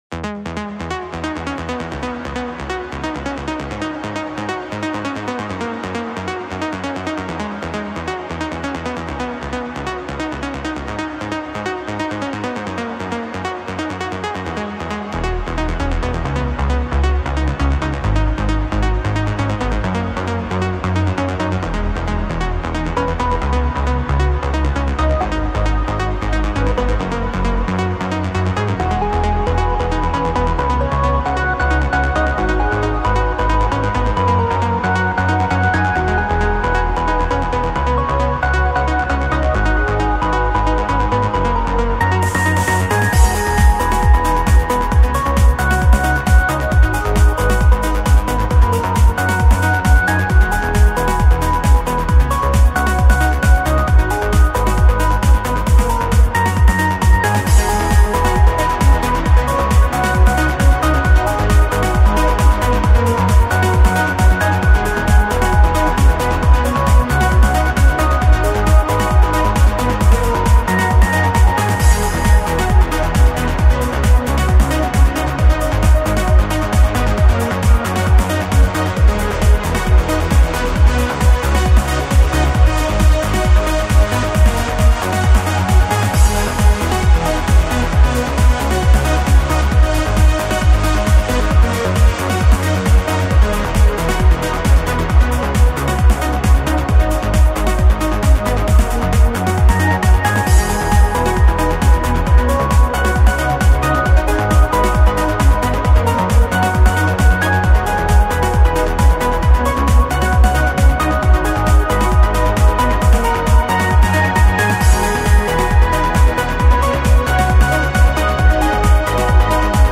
New example MIDI Tracker track - playing it LIVE in real-time:
MP3 Music file (4.5M) - Live recording MP3